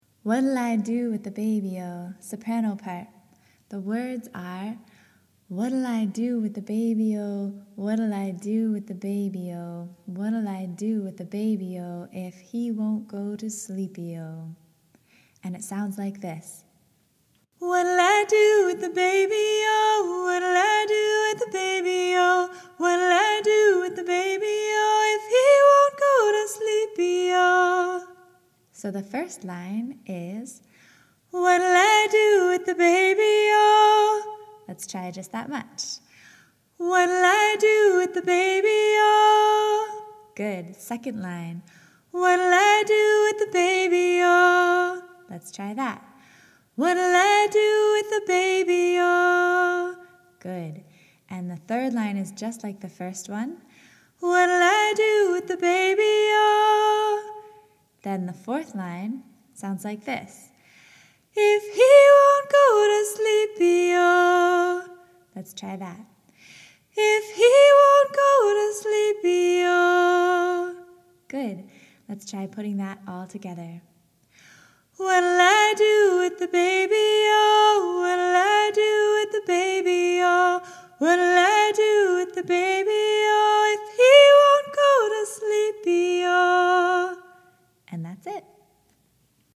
Let’s learn the chorus of this song together, starting with the melody.
Whatll-I-do_soprano.mp3